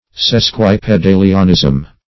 Search Result for " sesquipedalianism" : The Collaborative International Dictionary of English v.0.48: Sesquipedalianism \Ses`qui*pe*da"li*an*ism\, Sesquipedalism \Ses*quip"e*dal*ism\, n. Sesquipedality.
sesquipedalianism.mp3